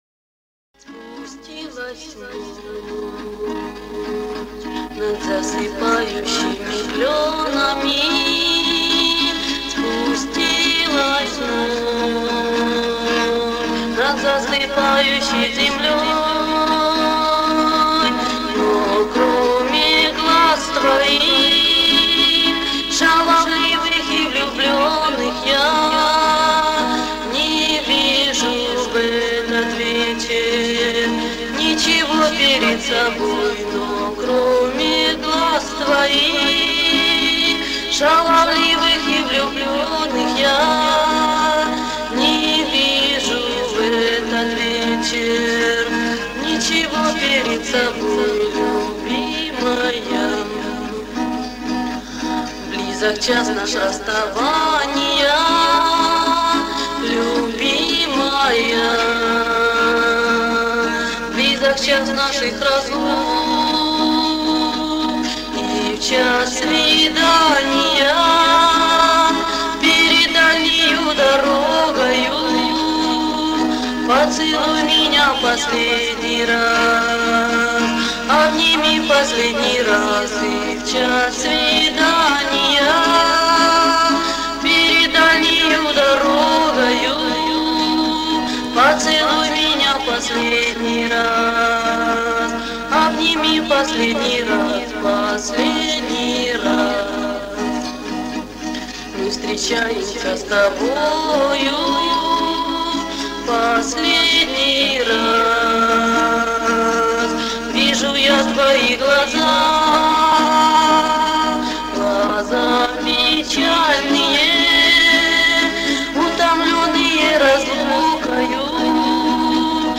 В середине семидесятых в Северодонецке Луганской области была такая исполнительница, но качество записи, сам понимаешь...